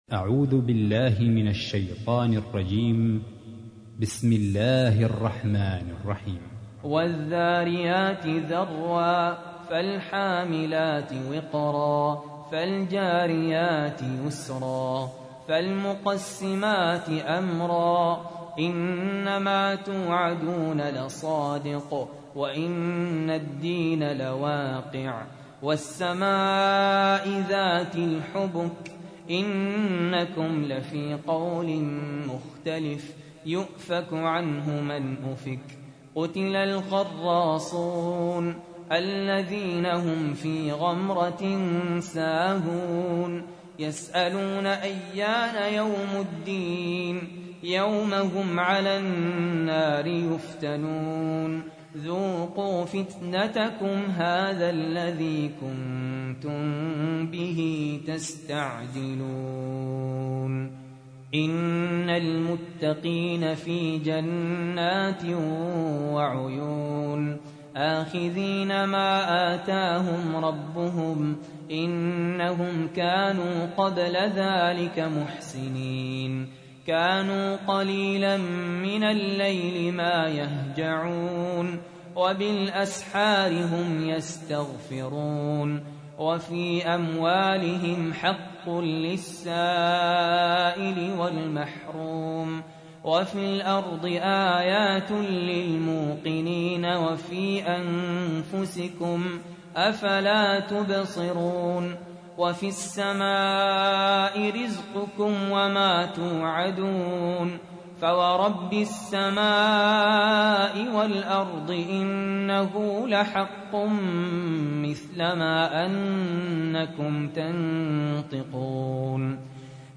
تحميل : 51. سورة الذاريات / القارئ سهل ياسين / القرآن الكريم / موقع يا حسين